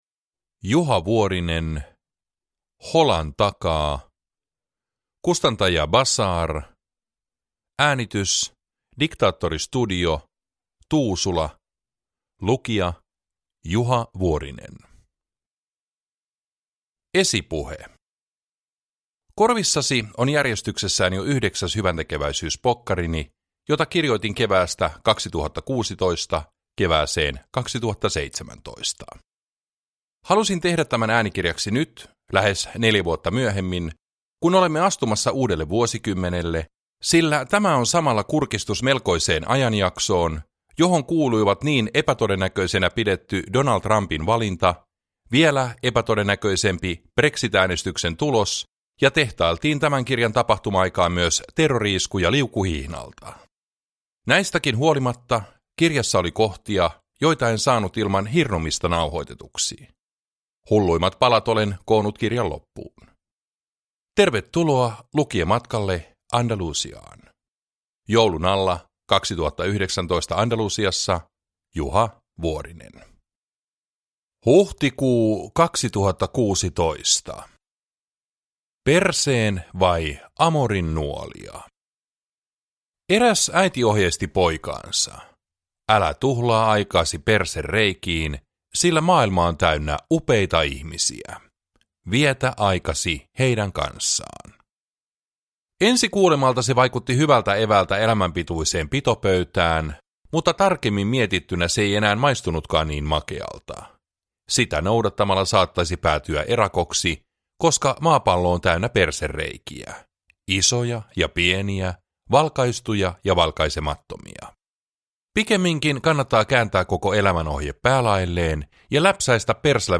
Uppläsare: Juha Vuorinen